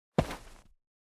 footstep.wav